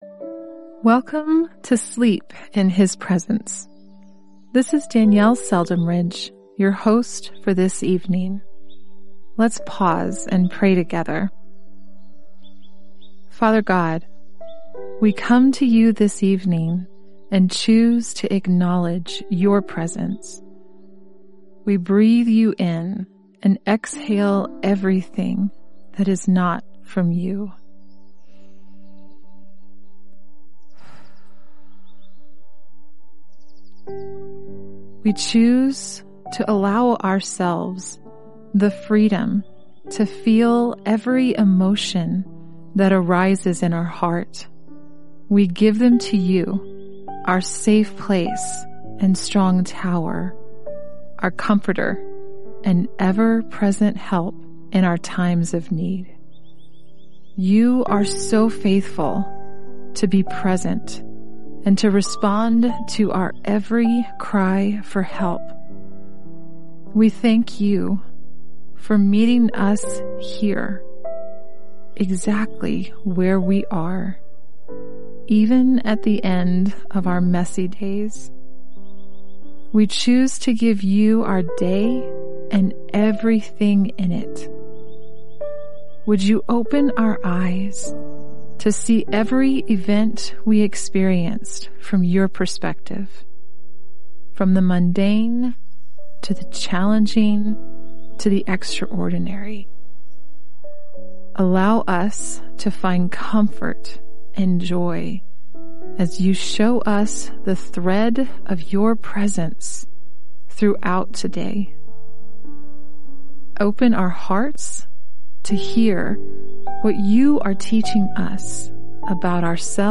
In this 6-day devotional, you’ll be gently led into the presence of God through heartfelt prayers, encouraging words, and powerful Scripture. Each night, a different host guides you to release your worries, embrace God’s love, and fall asleep knowing He is near.